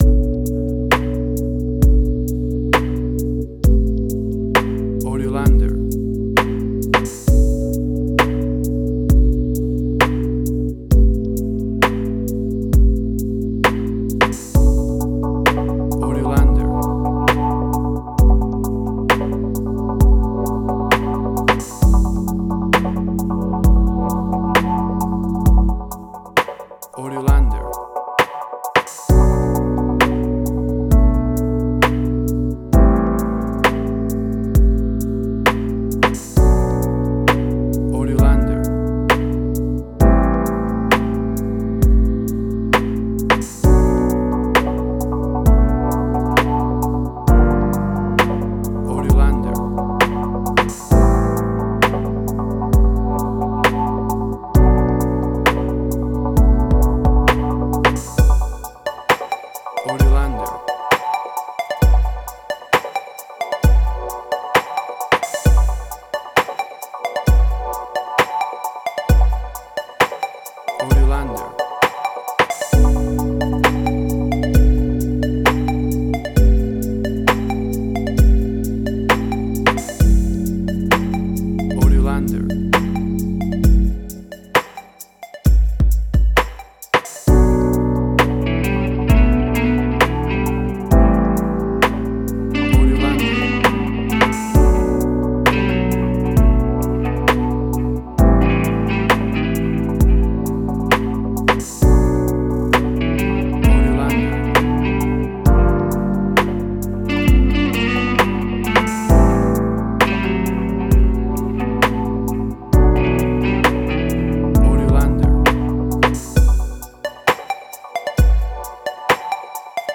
emotional music
Tempo (BPM): 66